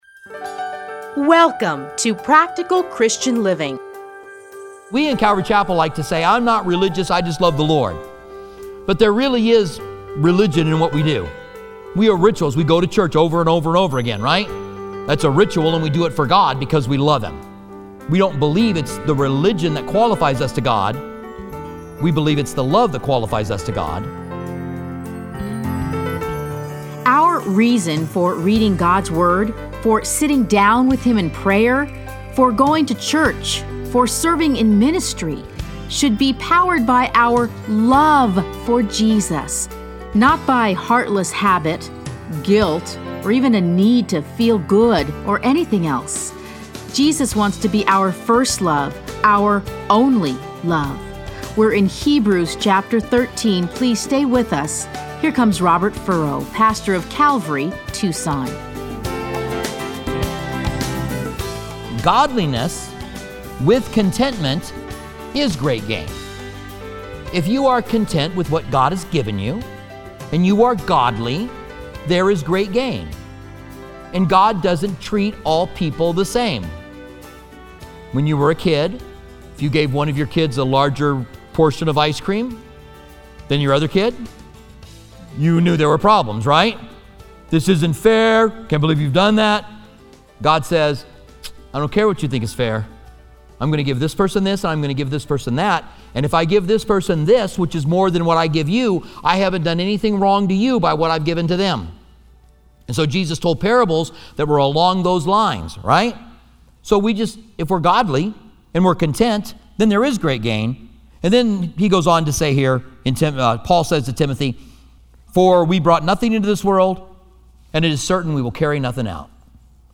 Listen to a teaching from Hebrews 13:1-25.